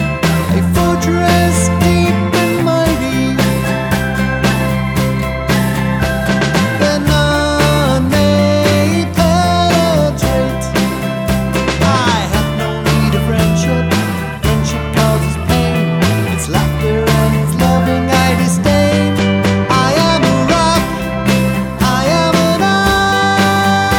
With Harmony Pop (1960s) 2:49 Buy £1.50